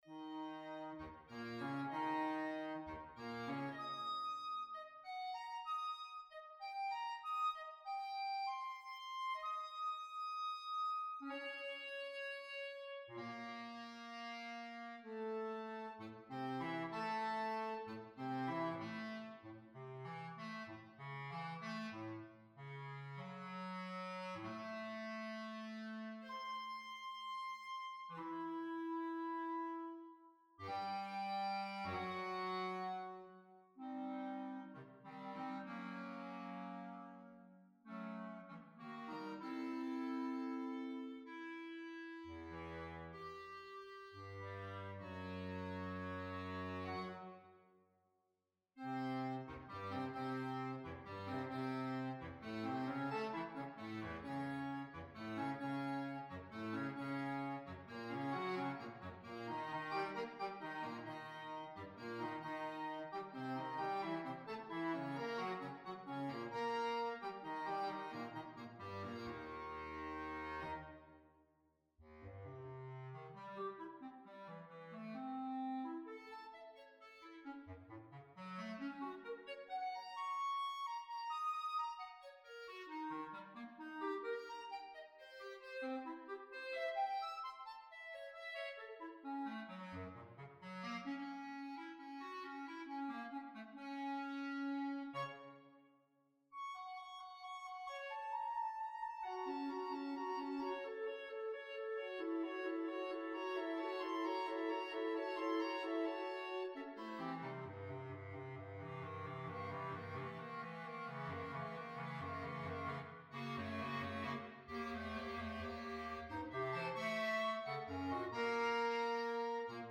Woodwind Ensembles
bullet  2024 Trio Con Brio (clarinet trio: Eb clarinet, Bb clarinet, bass clarinet) (5') [ACA]
(synthesized recording)